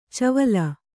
♪ cavala